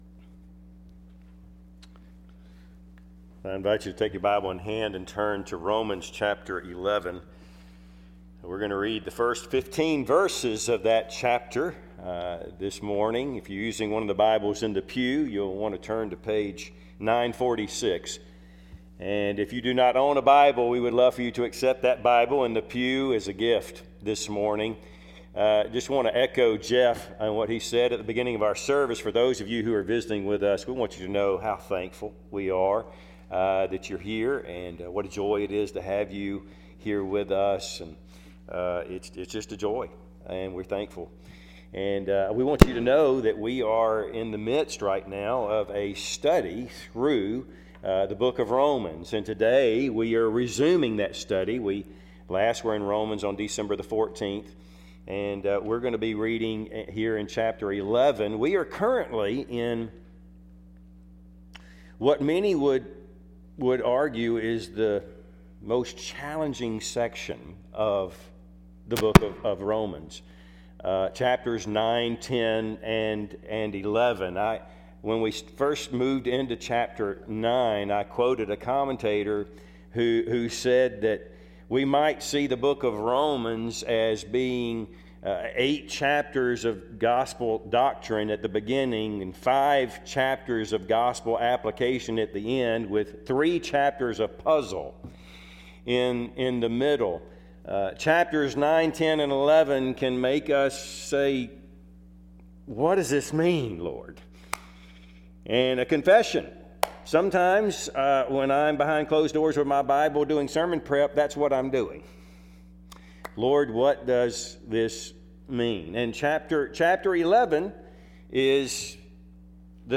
Passage: Romans 11:1-15 Service Type: Sunday AM